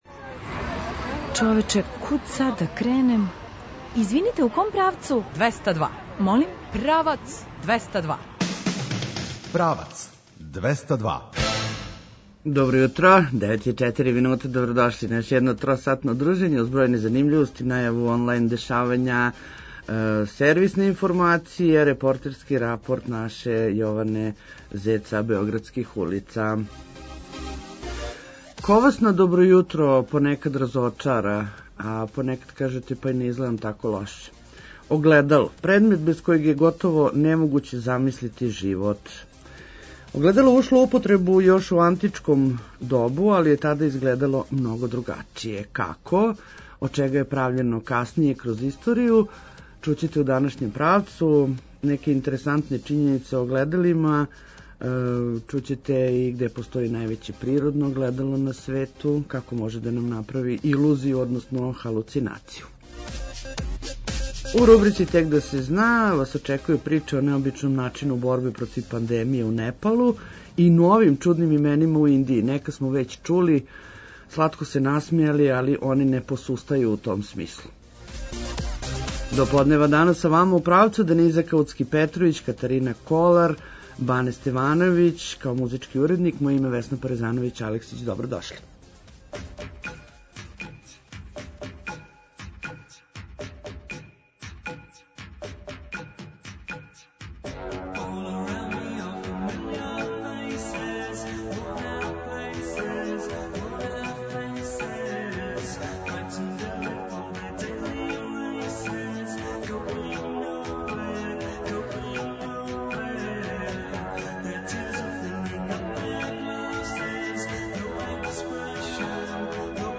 Неће изостати ни репортерски рапорт са београдских улица.